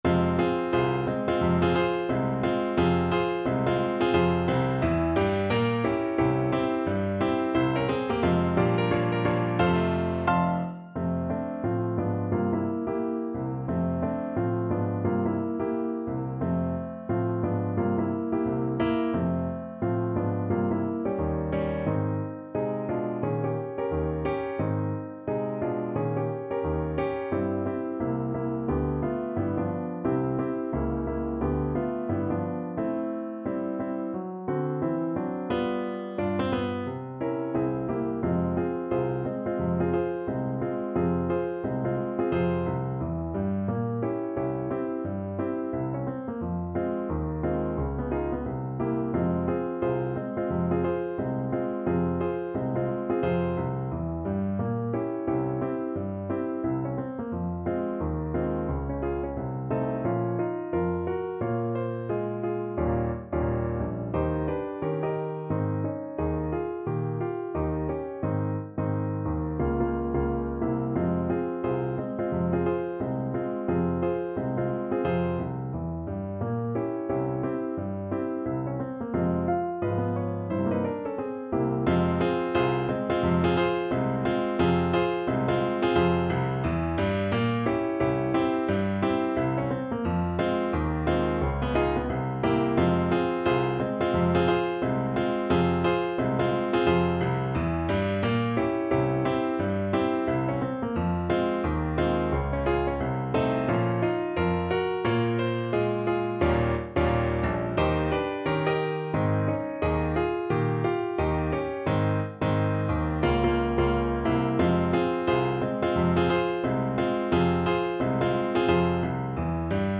~ = 176 Moderato
Jazz (View more Jazz Flute Music)